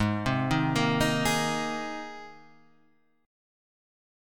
G#add9 chord {4 3 1 3 4 4} chord